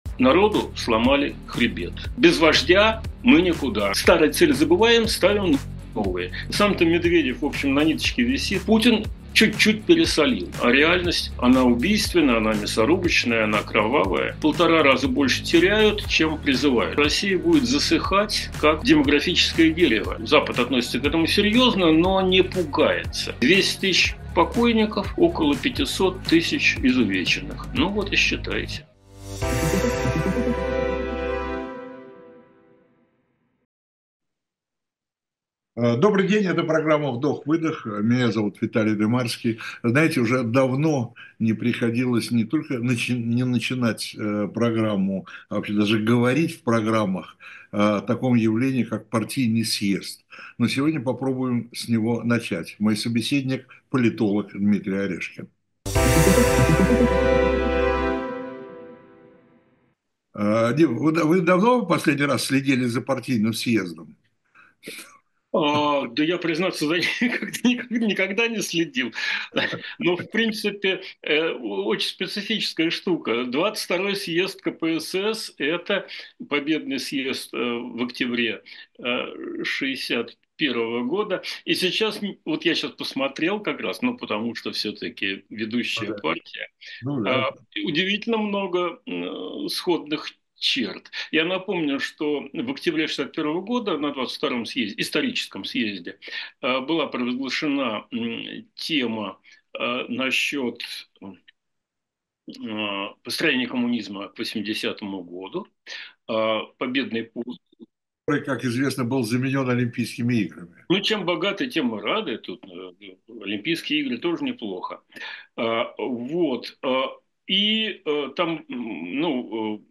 Эфир ведёт Виталий Дымарский
Медведев 17.12.24 Скачать Срочный сбор для «Эха» Подписаться на «Ходорковский live» Политолог Дмитрий Орешкин — гость программы «Вдох-выдох» на канале Ходорковский Live.